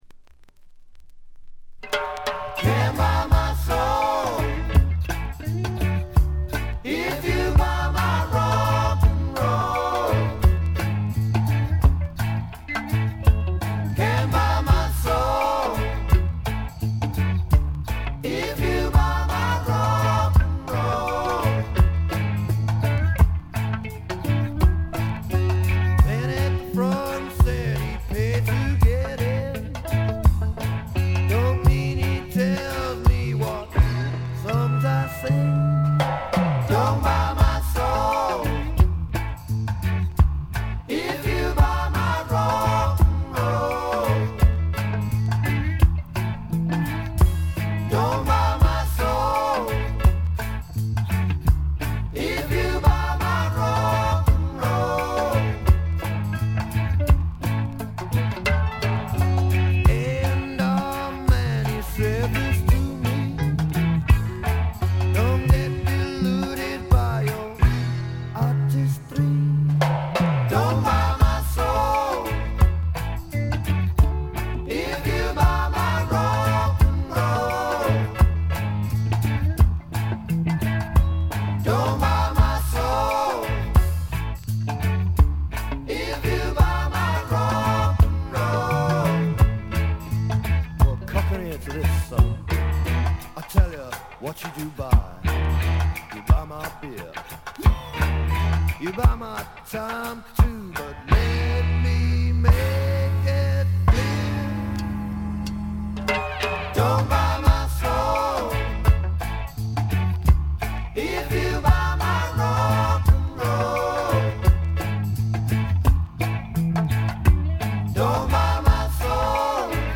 軽微なチリプチ少々、プツ音少々。
アメリカン・ドリーミーポップ風の曲やらアーシーなライト・レゲエに
試聴曲は現品からの取り込み音源です。
Acoustic Guitar, Electric Guitar, Vocals, Piano, Percussion
Recorded At - Riverside Studios, London